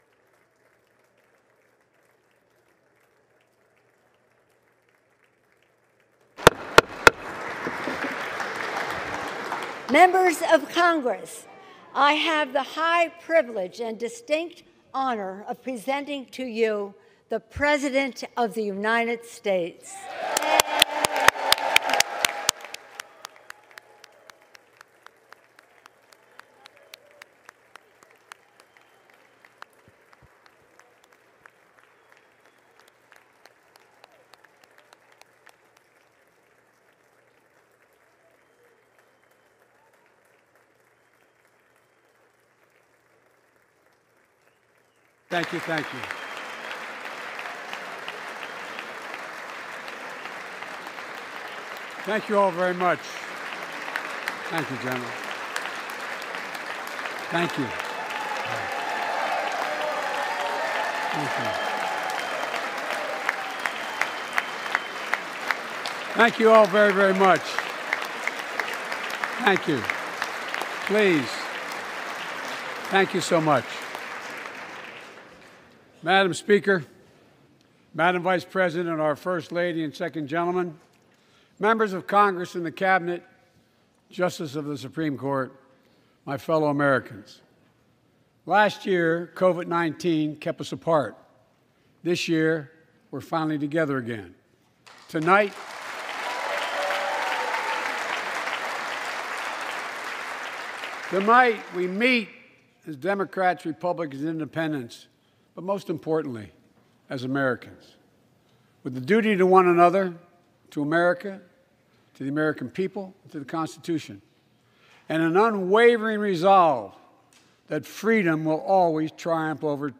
March 1, 2022: State of the Union Address | Miller Center
Presidential Speeches
biden_SOTU_2022.mp3